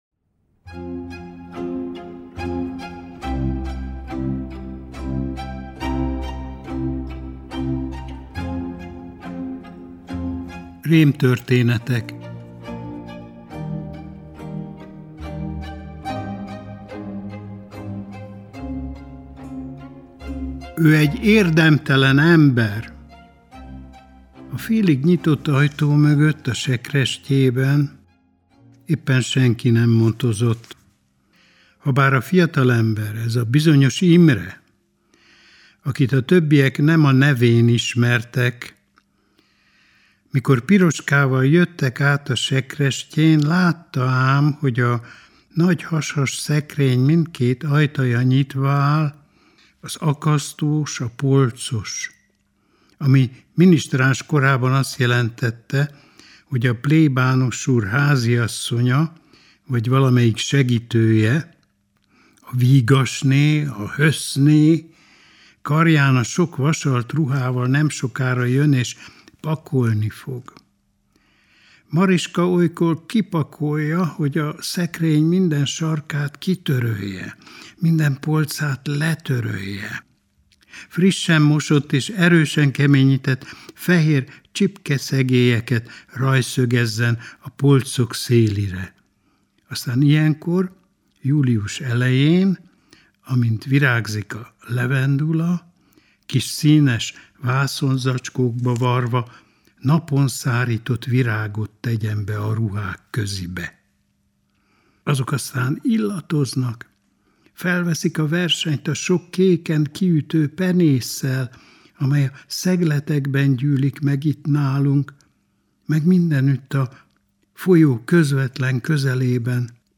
Nádas Péter olvas fel egy részletet a Rémtörténetek című, 2022-ben megjelent regényéből.